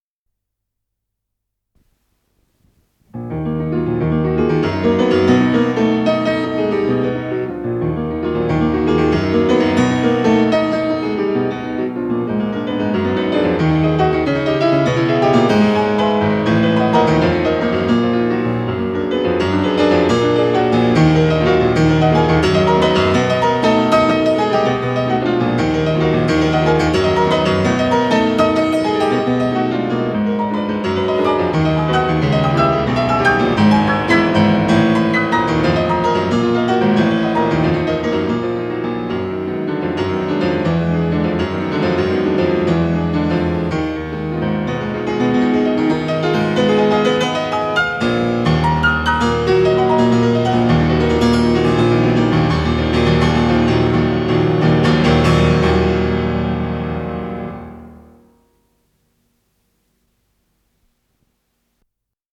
с профессиональной магнитной ленты
фортепиано